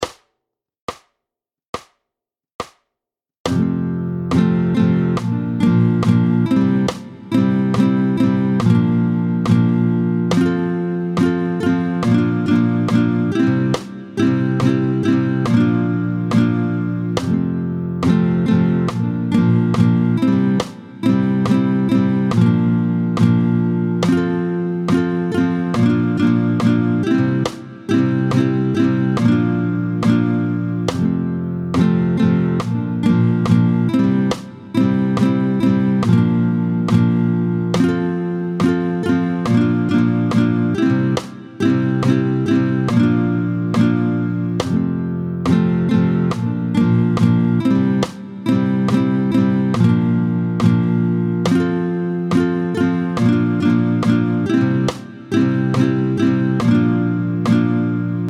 28-03 La bamba (Richie Valens), tempo 70